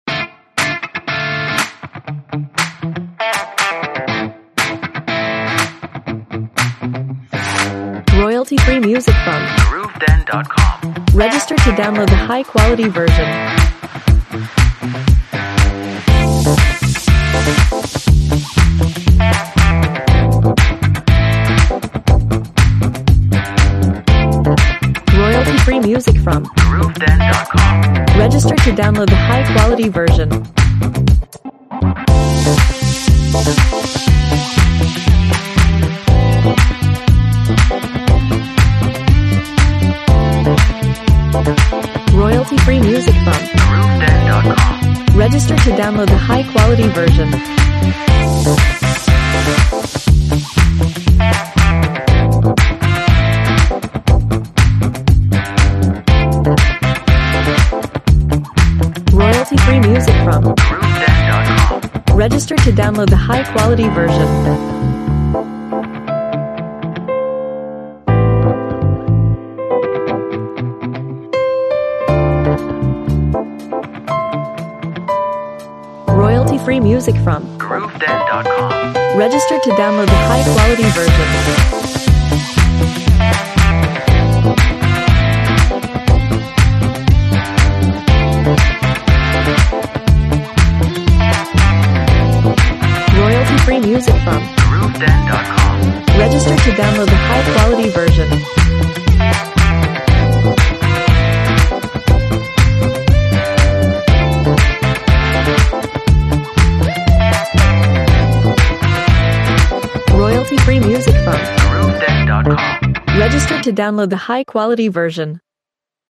rhythm guitars and funky bassline